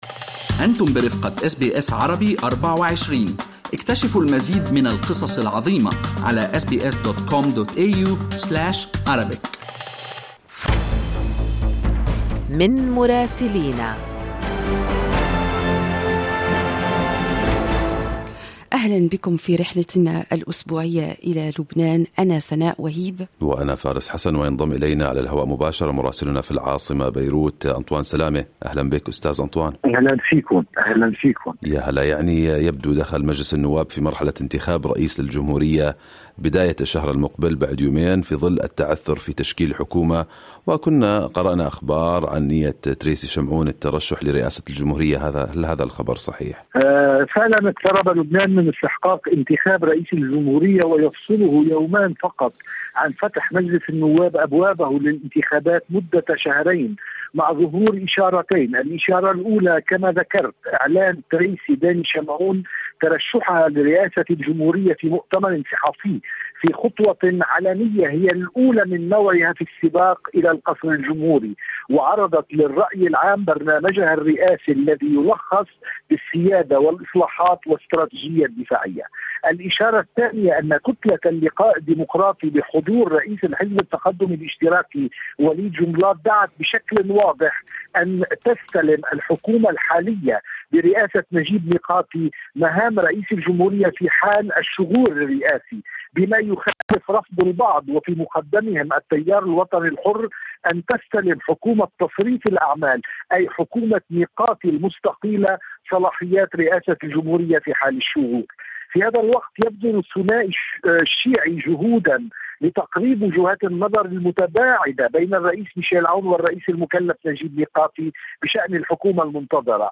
من مراسلينا: أخبار لبنان في أسبوع 30/8/2022